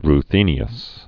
(r-thēnē-əs)